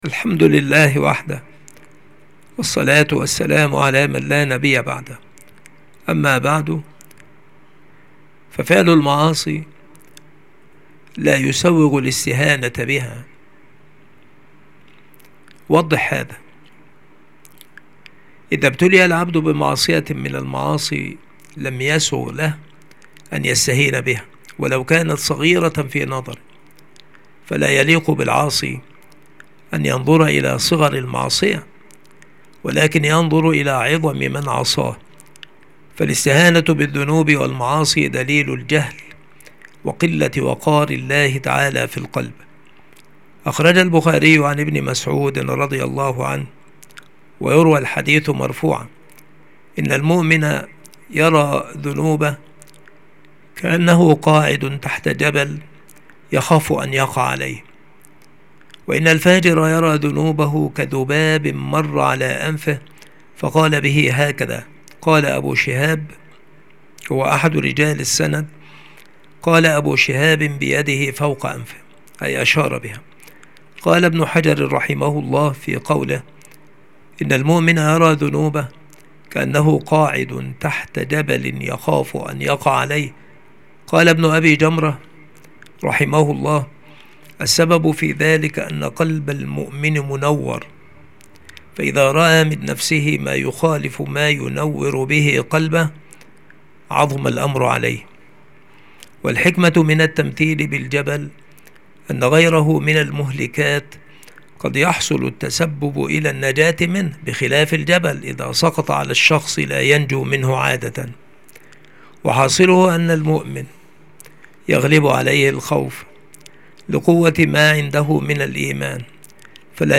مكان إلقاء هذه المحاضرة المكتبة - سبك الأحد - أشمون - محافظة المنوفية - مصر